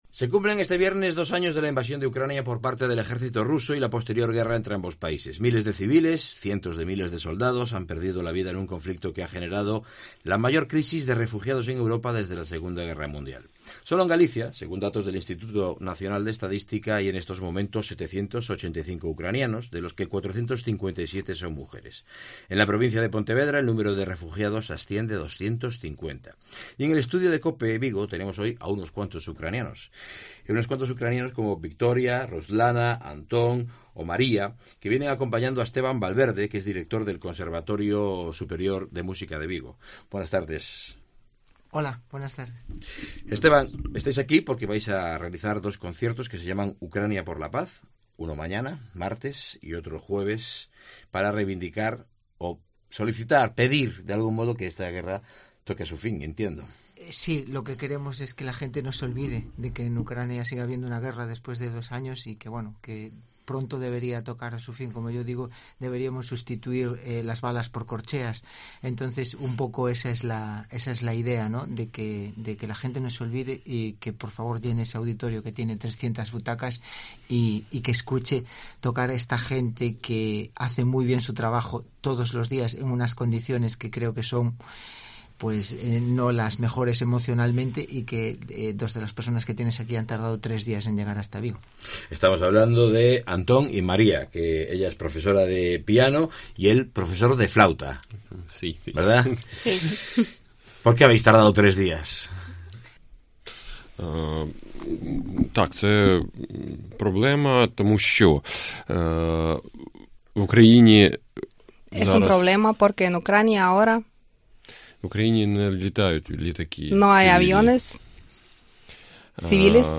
Entrevista músicos ucranianos en Vigo por la paz